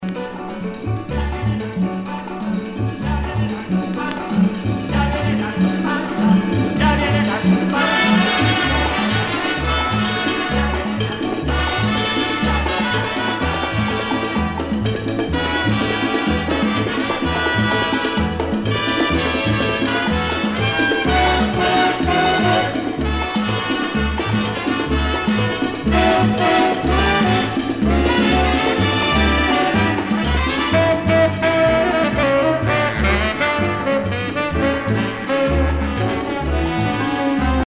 danse : congo
Pièce musicale éditée